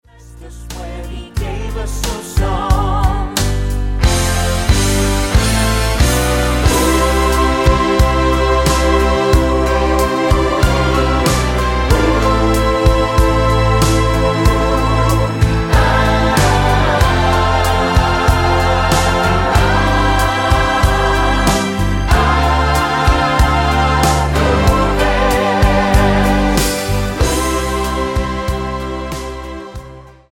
--> MP3 Demo abspielen...
Tonart:C mit Chor